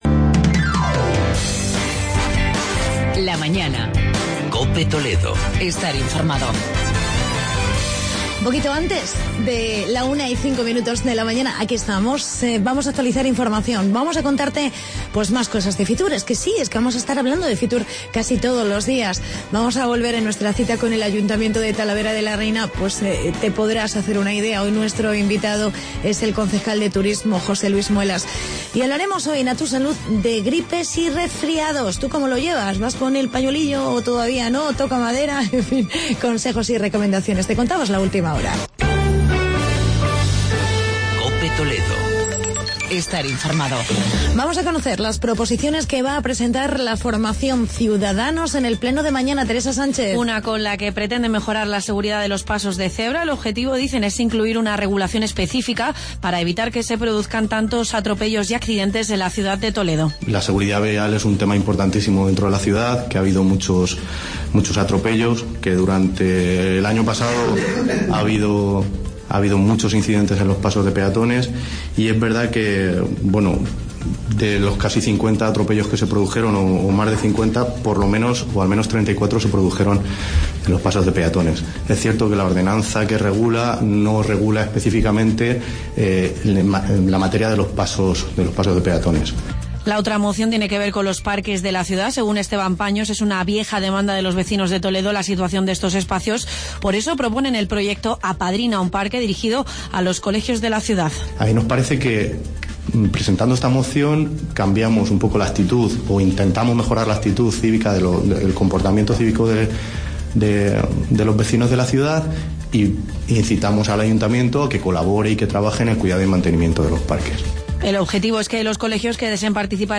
Entrevista con el concejal José Luis Muelas